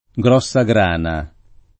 vai all'elenco alfabetico delle voci ingrandisci il carattere 100% rimpicciolisci il carattere stampa invia tramite posta elettronica codividi su Facebook grossagrana [ g r qSS a g r # na ] s. f.; pl. grossegrane (raro grossagrane )